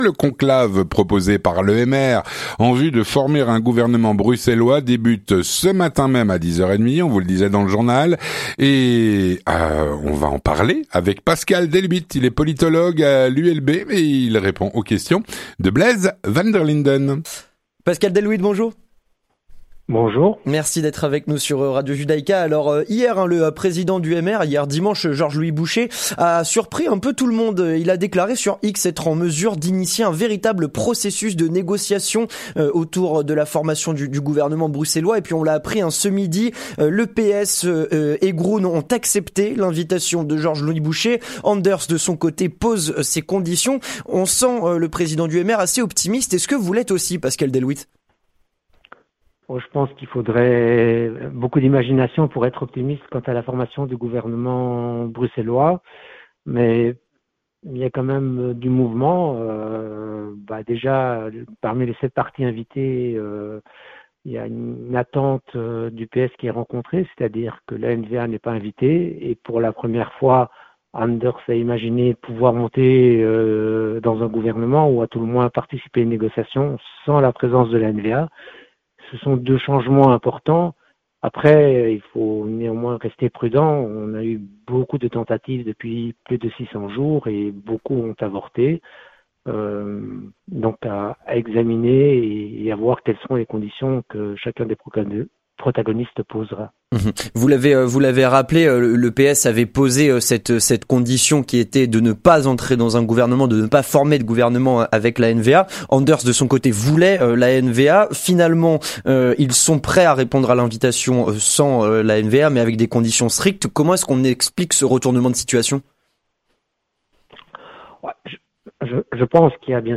L'entretien du 18H - Le conclave proposé par le MR en vue de former un gouvernement bruxellois débutera aujourd'hui à 10h30.